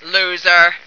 flak_m/sounds/female2/int/F2loser.ogg at efc08c3d1633b478afbfe5c214bbab017949b51b